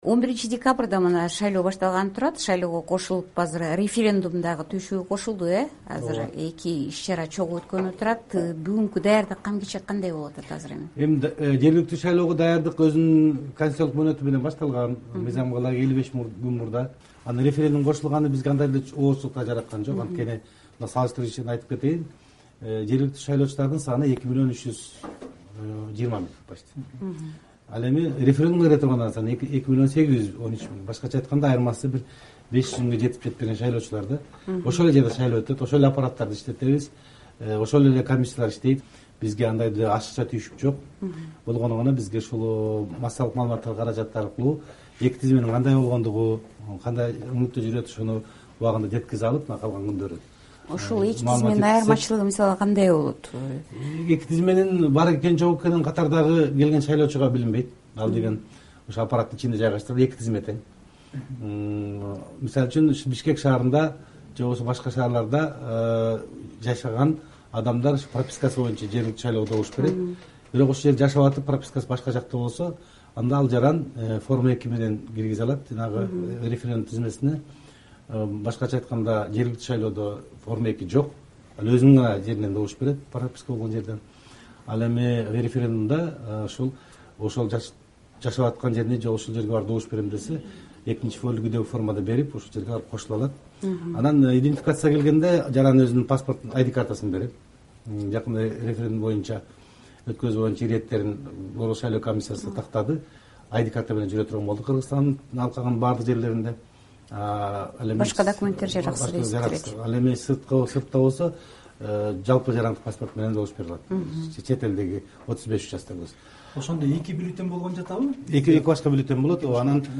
Бул тууралуу кыргыз өкмөтүнүн алдындагы Мамлекеттик каттоо кызматынын жетекчиси Тайырбек Сарпашев "Азаттыктын" 2+1 форматындагы эксклюзив маегинде билдирди.